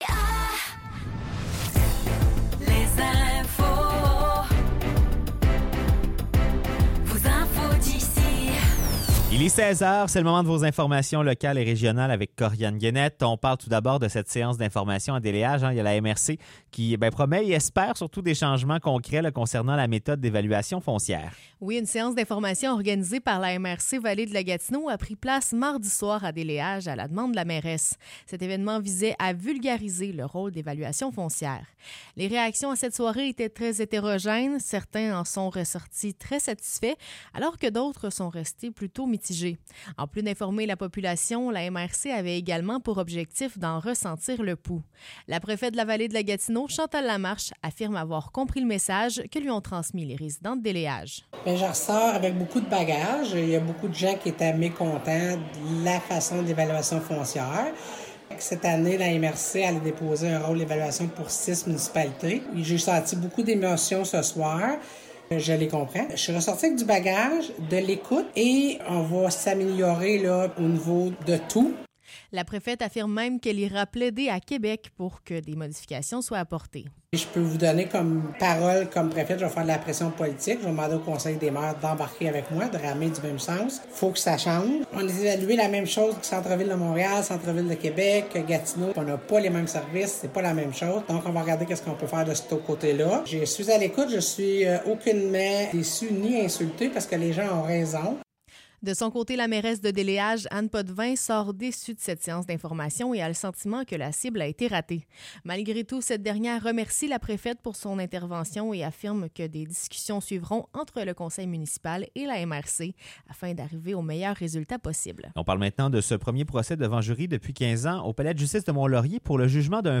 Nouvelles locales - 11 avril 2024 - 16 h